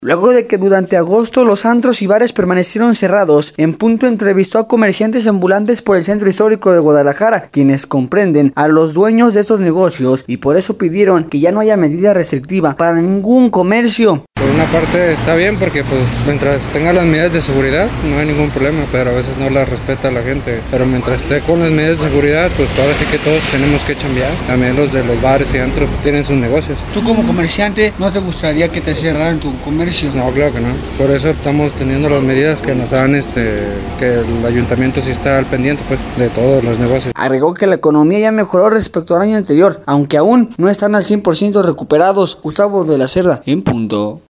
Luego de que durante agosto los antros y bares permanecieron cerrados, En Punto, entrevistó a comerciantes ambulantes por el Centro Histórico de Guadalajara, quienes comprenden a los dueños de estos negocios, y por eso pidieron que ya no haya medida restrictiva, para ningún comercio.